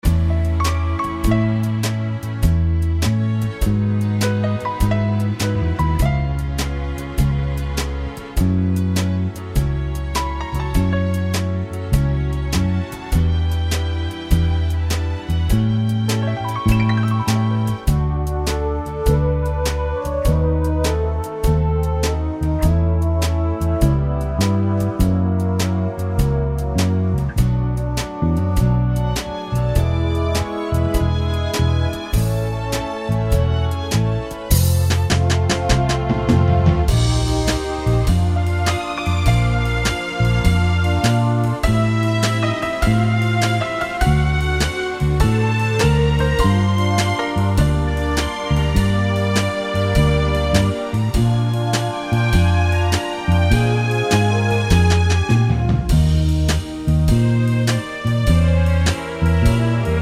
no Backing Vocals Oldies (Male) 3:03 Buy £1.50